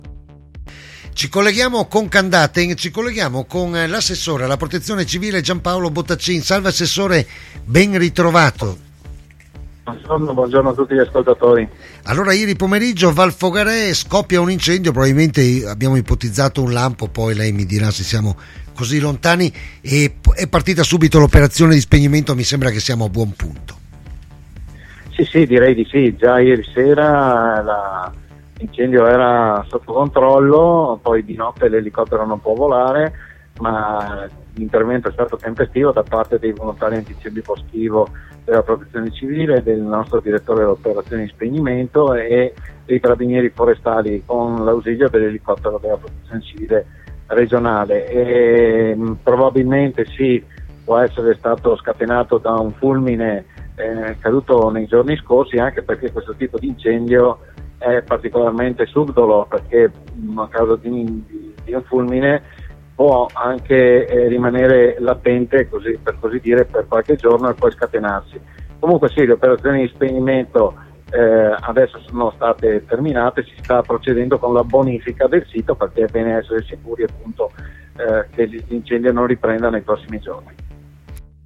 NELLE PAROLE dell’assessore Gianpaolo Bottacini la situazione sopra Candaten (Sedico) dove ieri pomeriggio è scoppiato un incendio